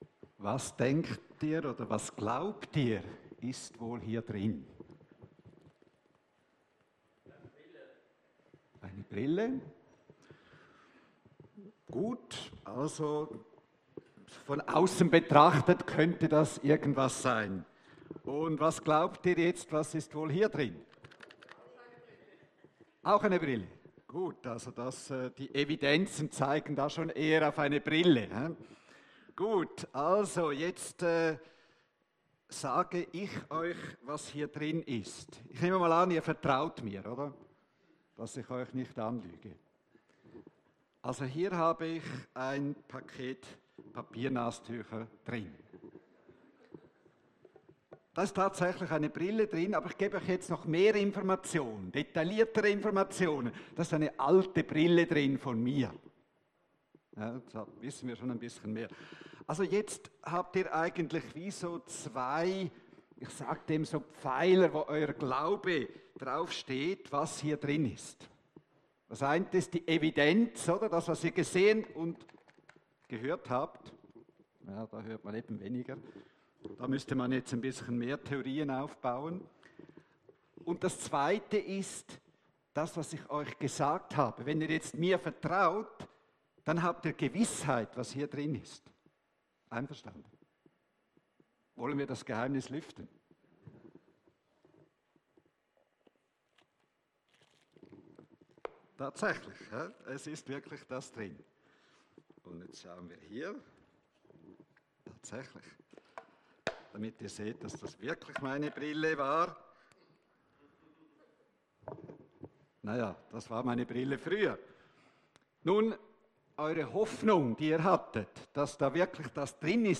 Predigt nach Hebräer 11, 1-7